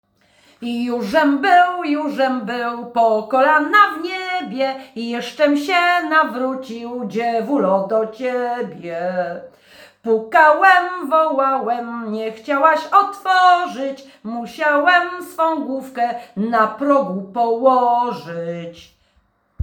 Jużżem był, jużżem był – Żeńska Kapela Ludowa Zagłębianki
Nagranie współczesne